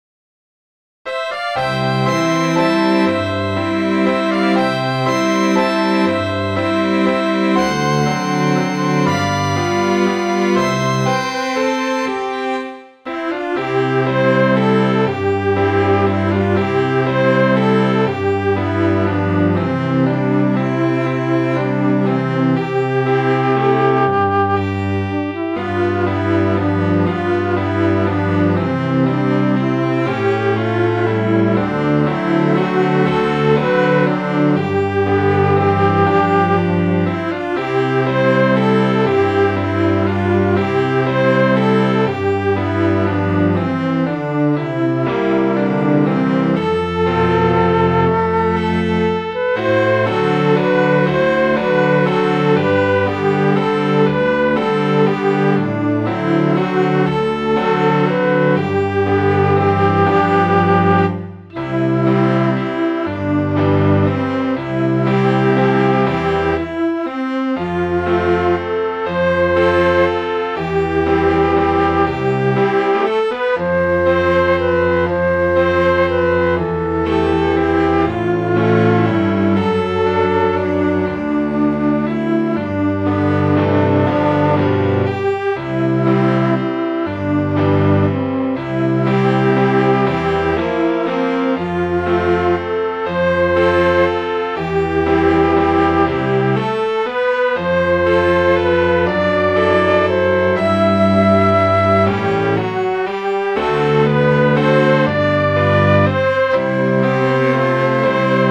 The first midi is of the chorus only. The second is the entire song.